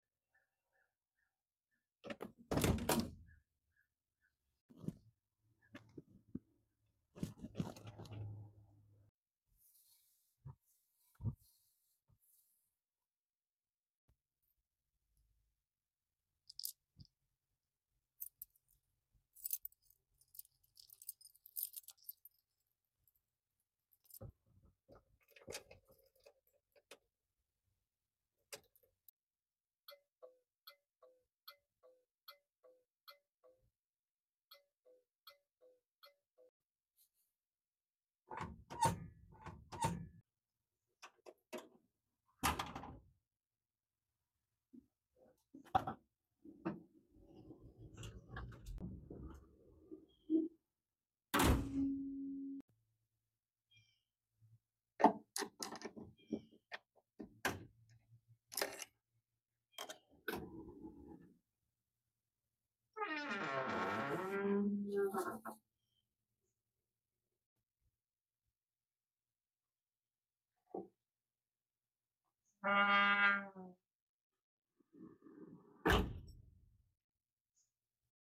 Classic Cars Sounds Mopar Edition sound effects free download